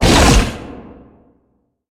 Sfx_creature_bruteshark_chase_os_03.ogg